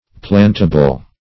Search Result for " plantable" : The Collaborative International Dictionary of English v.0.48: Plantable \Plant"a*ble\, a. Capable of being planted; fit to be planted.
plantable.mp3